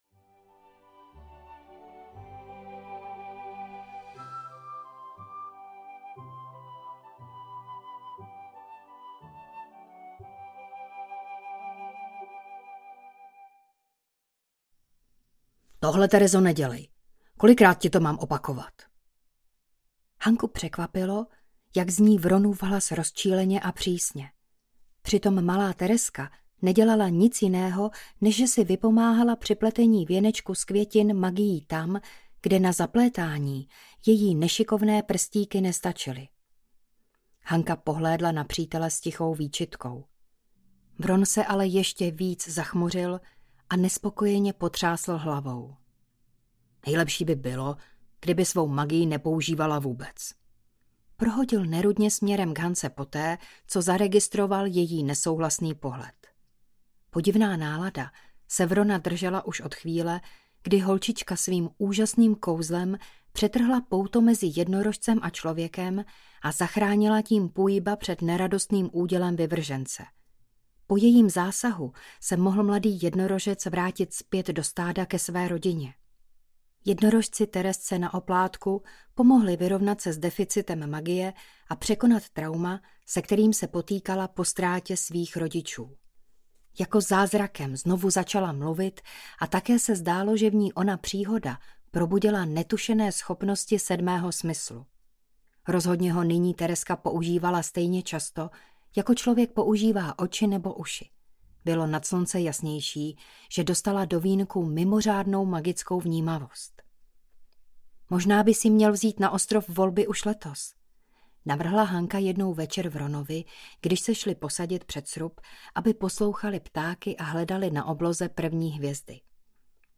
Bez práva na život audiokniha
Ukázka z knihy